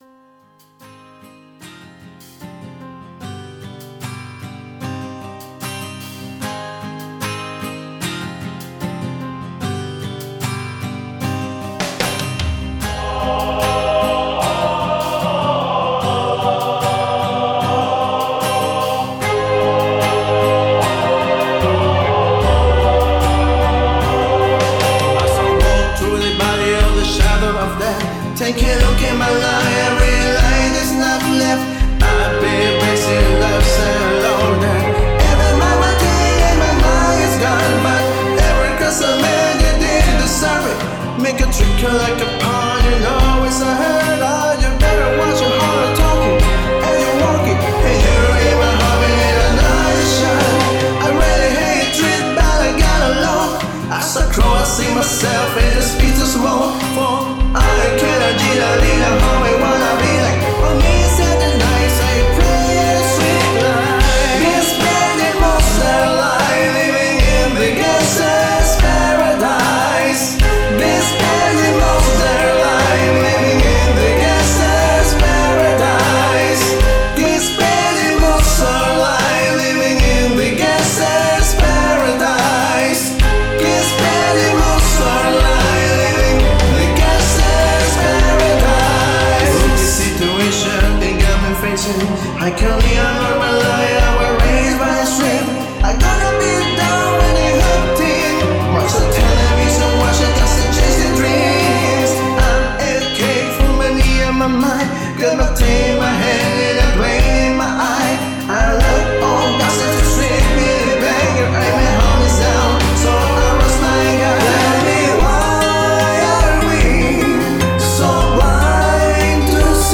versión acústica